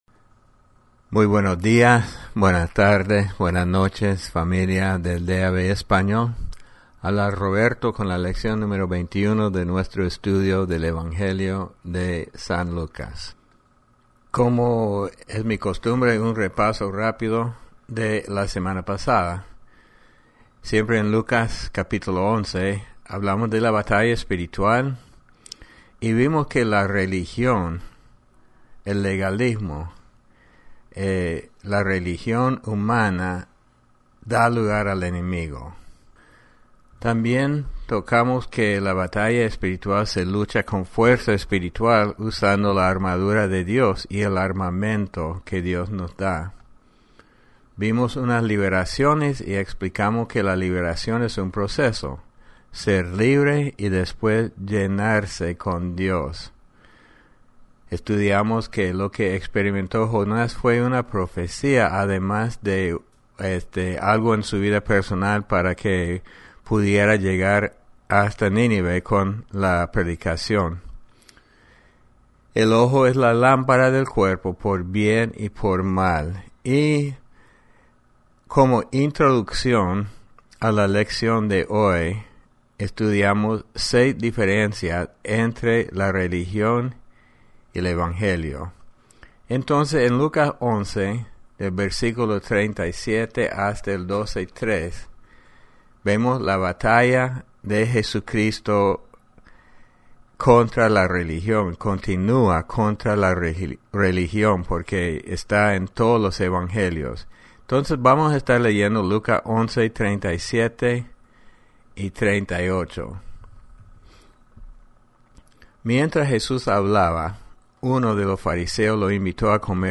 Lección #21 Evangelio de San Lucas